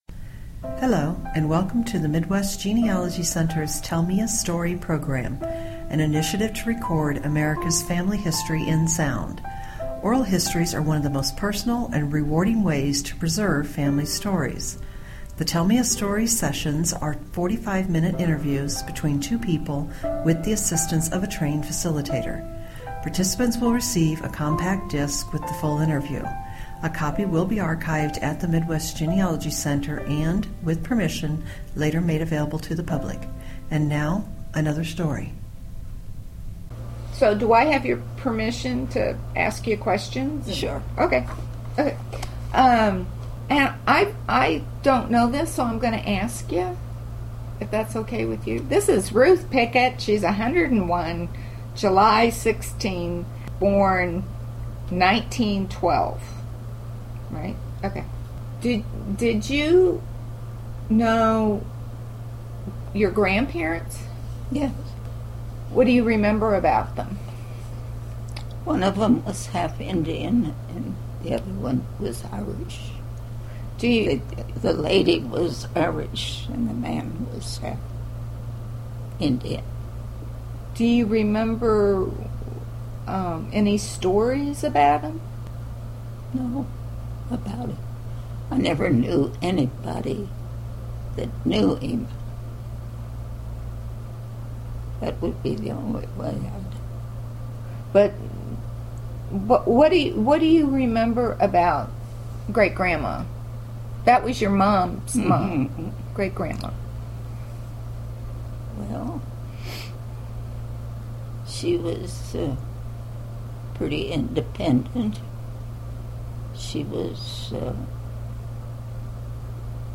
Oral Interview
Oral history